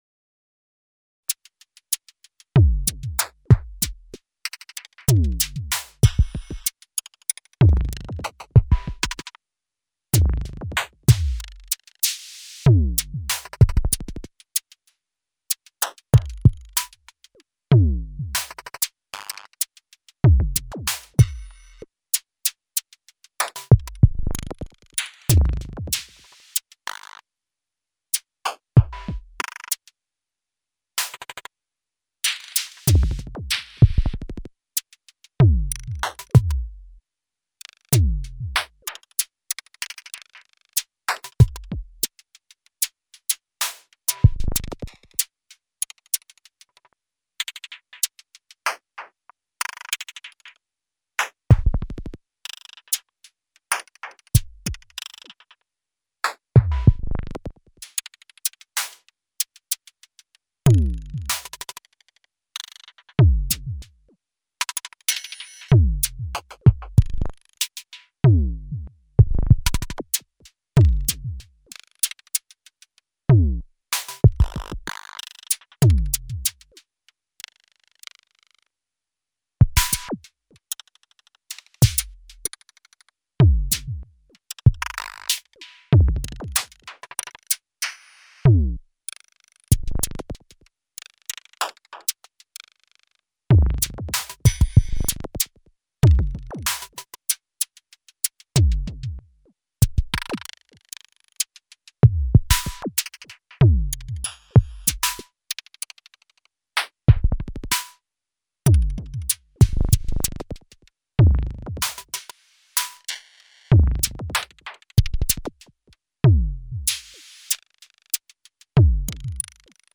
Another test, only drums, no FX, one bar loop, play rec, compressor.